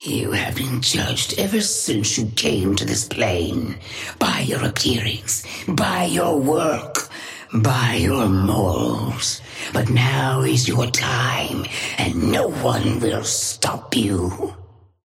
Sapphire Flame voice line - You have been judged ever since you came to this plane.
Patron_female_ally_wrecker_start_03.mp3